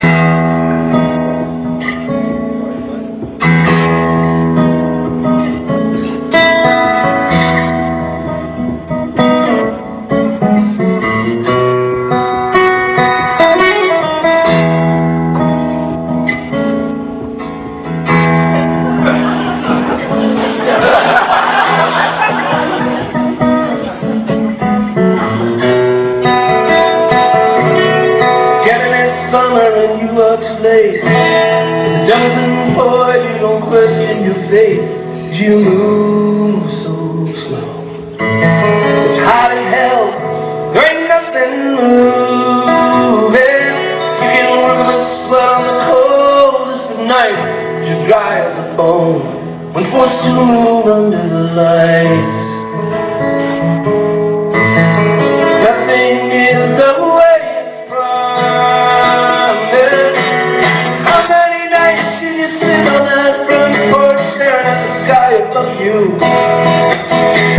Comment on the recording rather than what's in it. Concert Pictures and Movies The Octagon